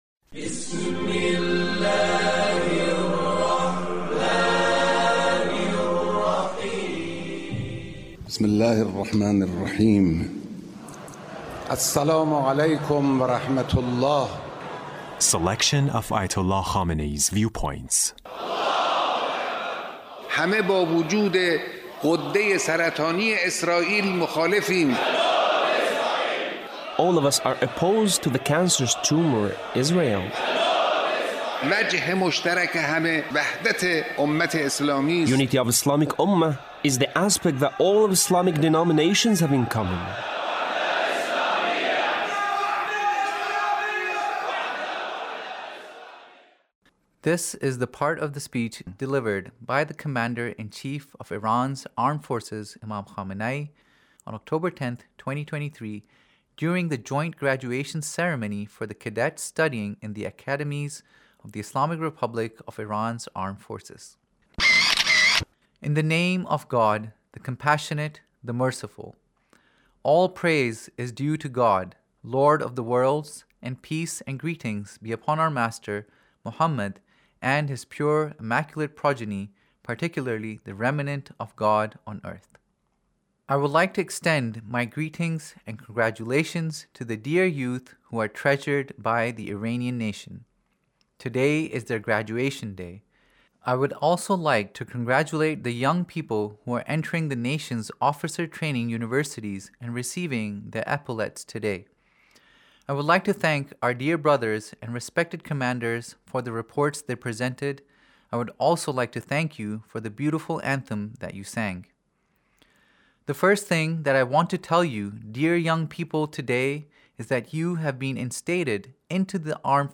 Leader's Speech in a meeting with Graduated Armed Forces